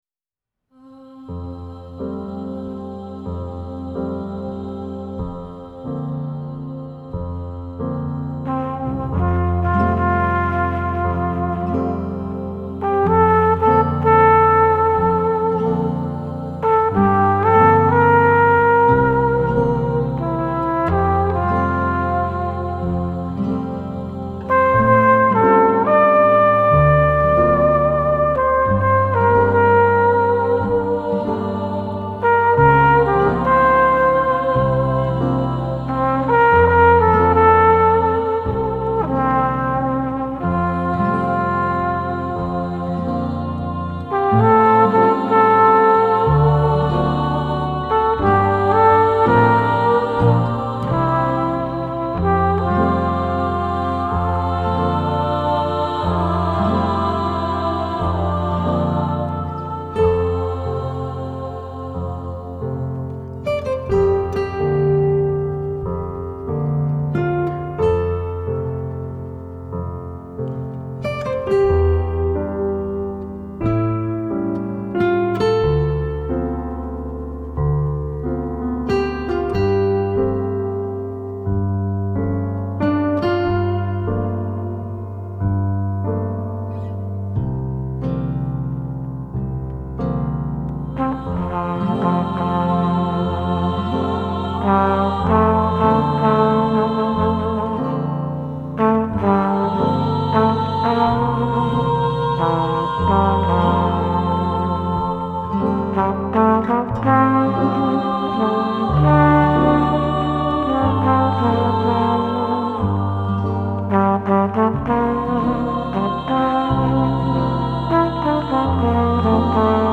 Genre : Jazz contemporain